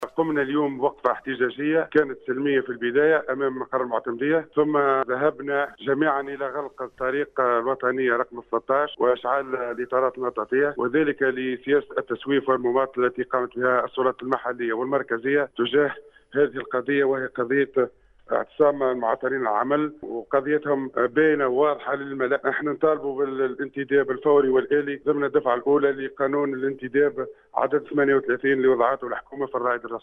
أحد المحتجين يتحدث للسيليوم اف ام